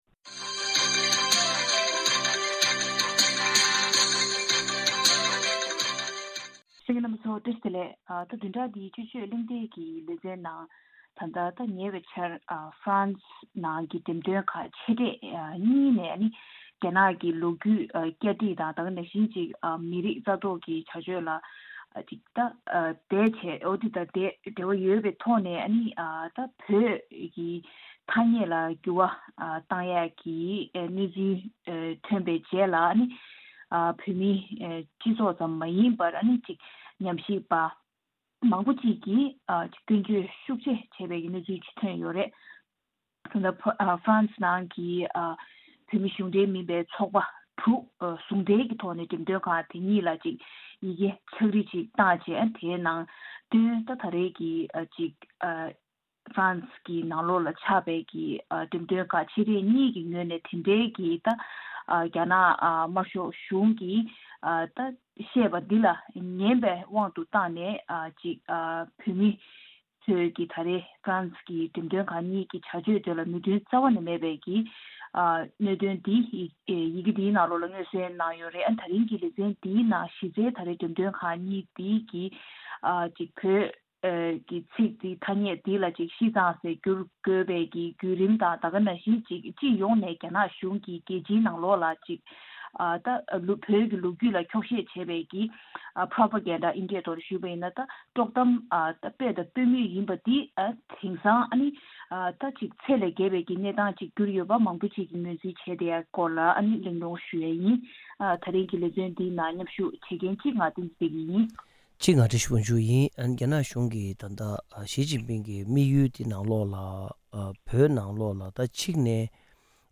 དཔྱད་གཞིའི་གླེང་མོལ་ཞུས་པར་གསན་རོགས་གནང་།